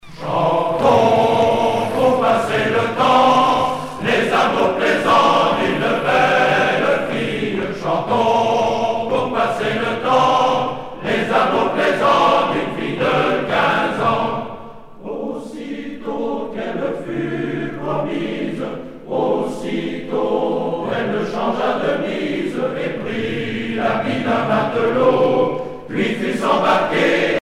Ensemble choral
Pièce musicale éditée